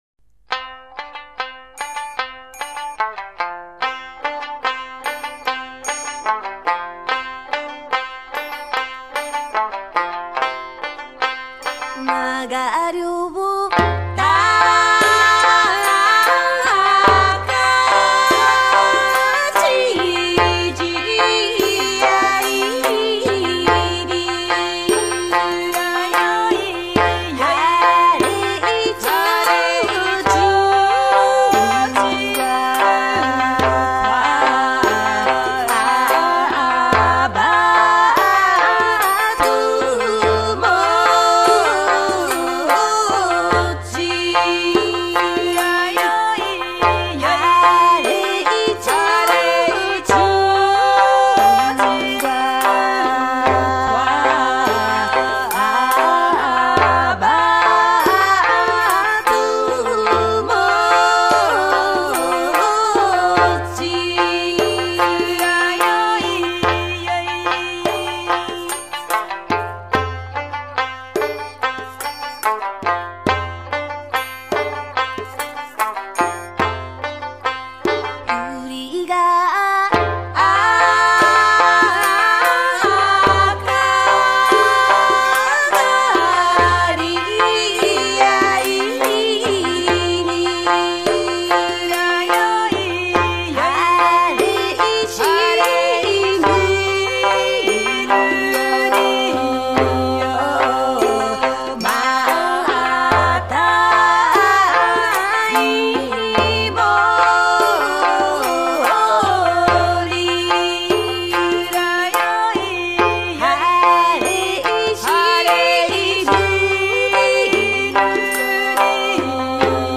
音乐类型：世界音乐
日本 / 冲绳、台湾、太平洋群岛民歌